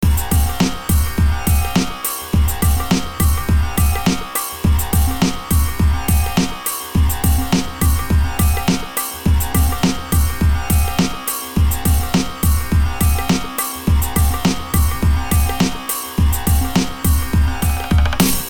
描述：Rhodes, Delay, and a bad mood.
Tag: 104 bpm Chill Out Loops Organ Loops 1.55 MB wav Key : Unknown